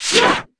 Index of /App/sound/monster/ice_snow_witch
attack_1.wav